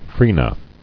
[fre·na]